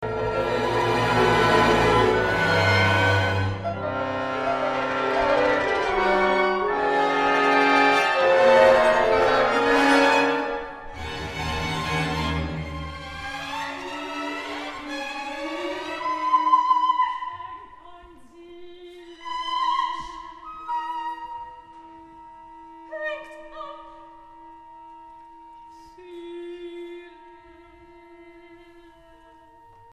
Estreno mundial.